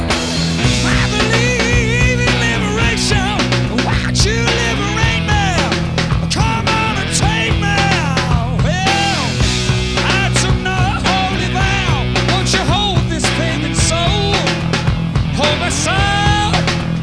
bass
drums
This one's raw and retro, with equal parts of anger and joy.
rhythm guitar